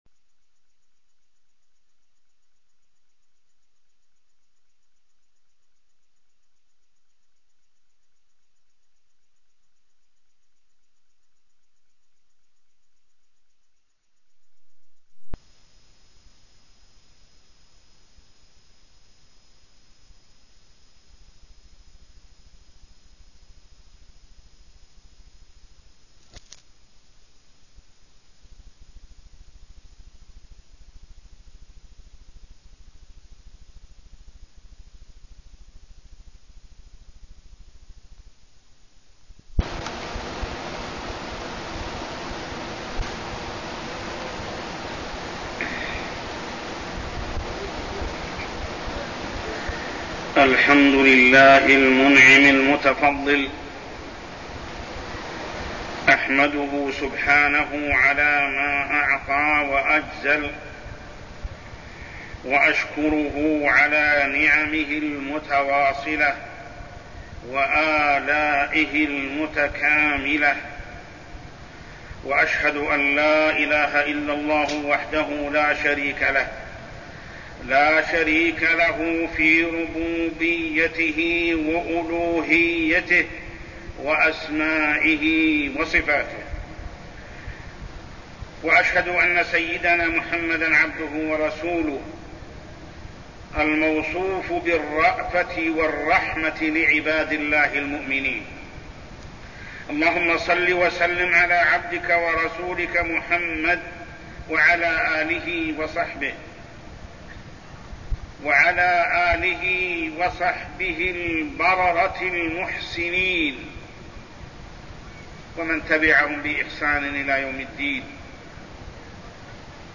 تاريخ النشر ١٥ ذو القعدة ١٤١١ هـ المكان: المسجد الحرام الشيخ: محمد بن عبد الله السبيل محمد بن عبد الله السبيل صلة الرحم